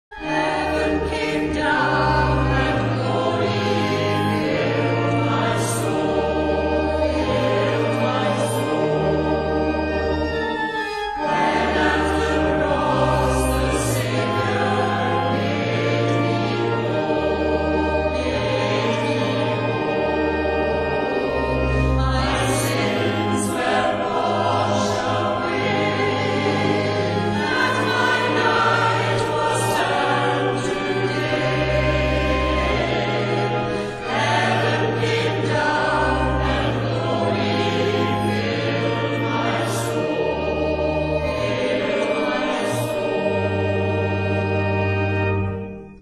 Maybole Churches have a long history of Choral Singing.
Praise music performed in these historic buildings.